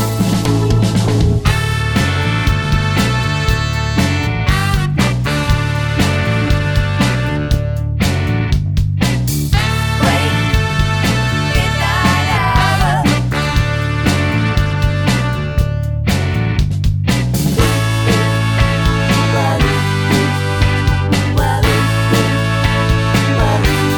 no Backing Vocals Soundtracks 2:22 Buy £1.50